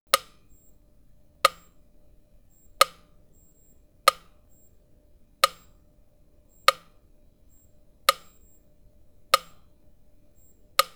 Tiempo largo en un metrónomo
largo
metrónomo